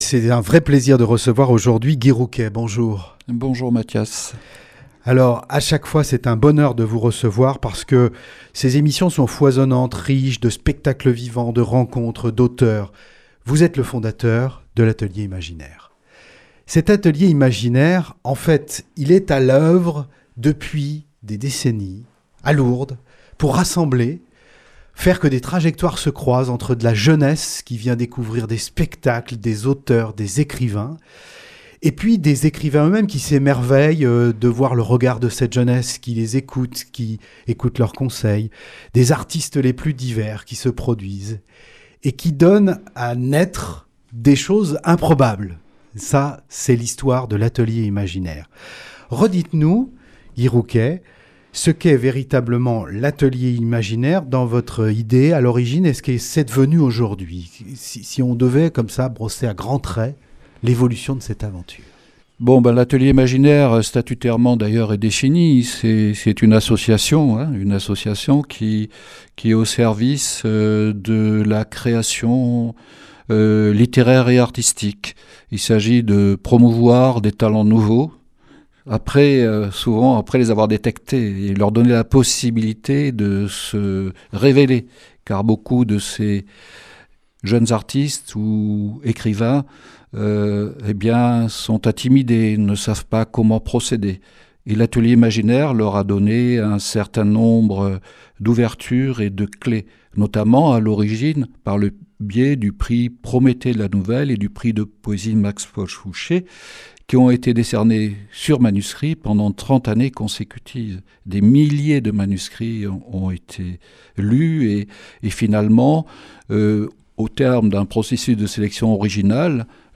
Interview Reportage